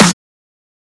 edm-clap-46.wav